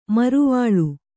♪ mārvaḷa